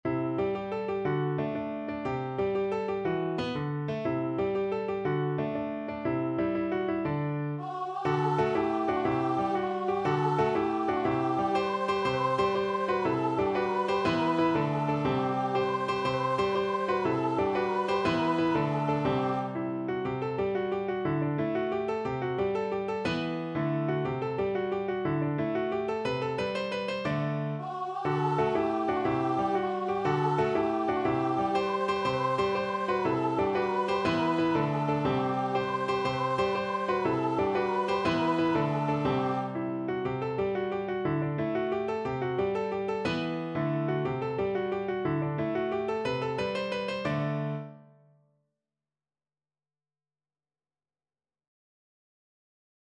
Voice
6/8 (View more 6/8 Music)
C major (Sounding Pitch) (View more C major Music for Voice )
With energy .=c.120
Classical (View more Classical Voice Music)